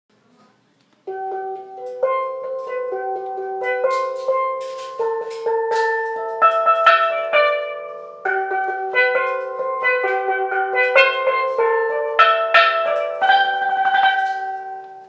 Toy Steel Drum
Click to hear the toy steel drum.
toy-steel-drum.m4a